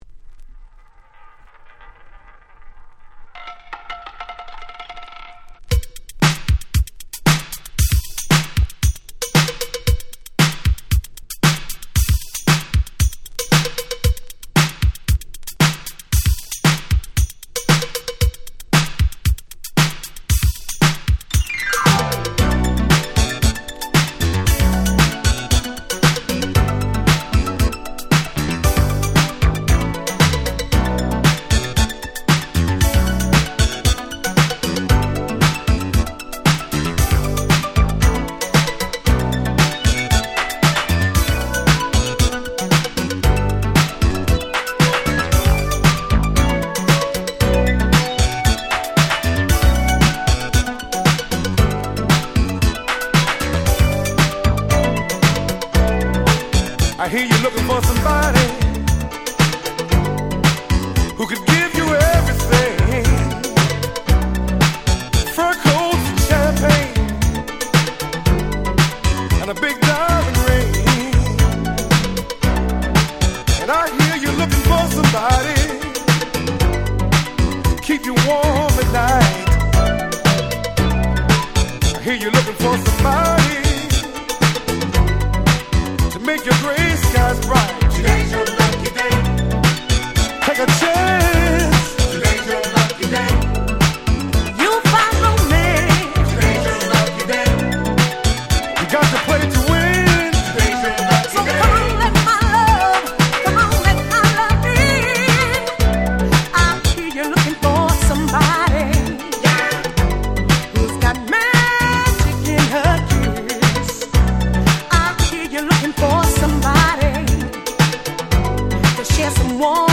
84' Very Nice Disco / Boogie !!
どうしてもSweet Soulなイメージが先行してしまう彼ら、でもDiscoだって最高なんです！！